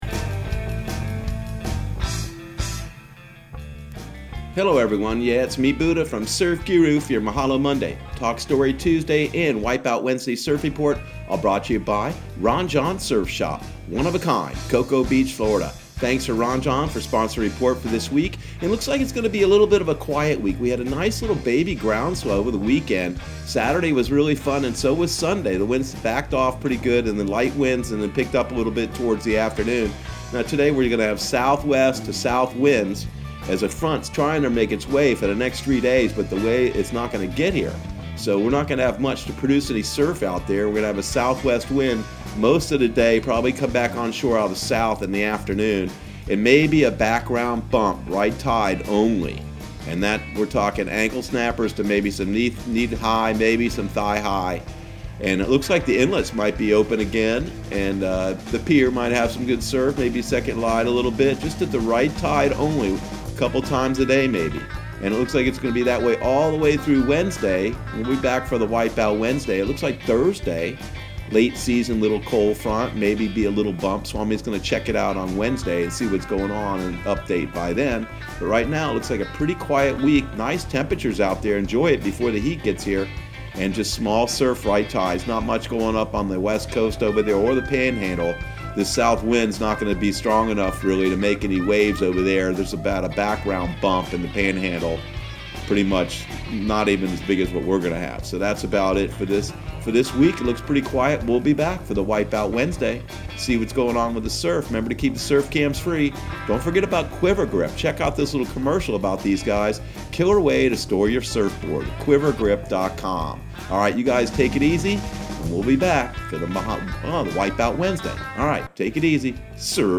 Surf Guru Surf Report and Forecast 05/04/2020 Audio surf report and surf forecast on May 04 for Central Florida and the Southeast.